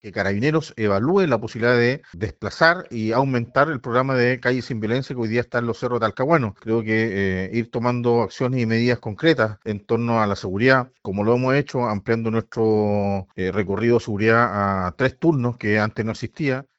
El alcalde de la comuna, Eduardo Saavedra, lamentó que Talcahuano se incorporara a las estadísticas de violencia, y señaló que han pedido a Carabineros un aumento de dotación, entre otras medidas.